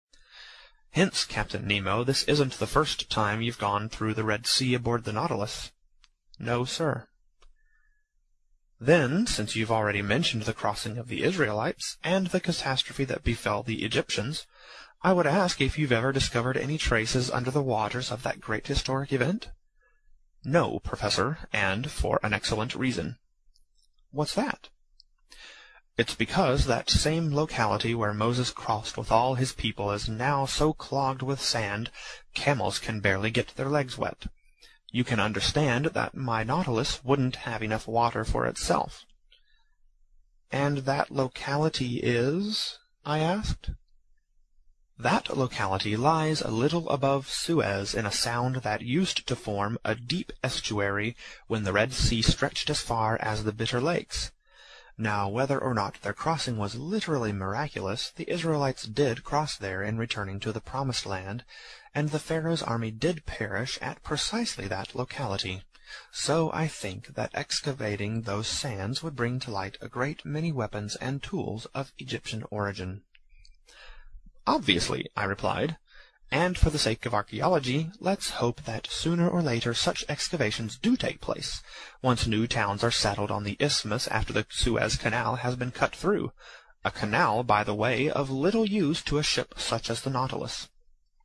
英语听书《海底两万里》第351期 第23章 珊瑚王国(48) 听力文件下载—在线英语听力室